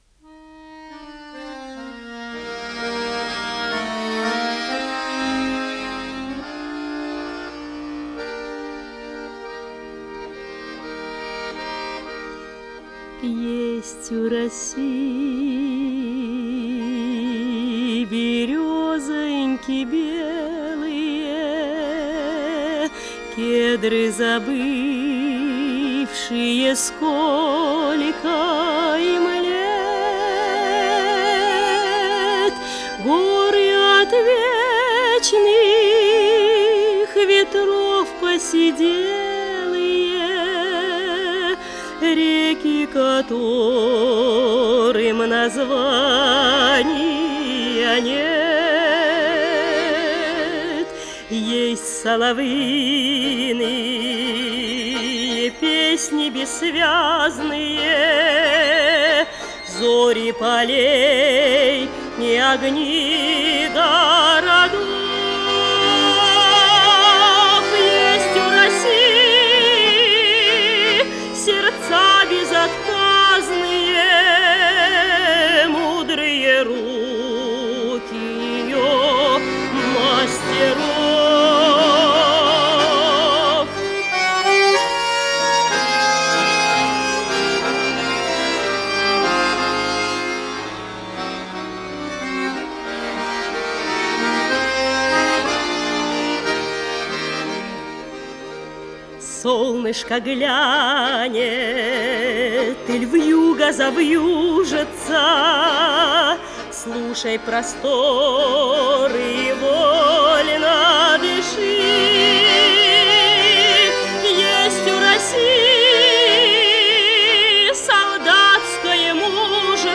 с профессиональной магнитной ленты
АккомпаниментДуэт баянистов